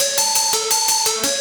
Index of /musicradar/shimmer-and-sparkle-samples/170bpm
SaS_Arp01_170-A.wav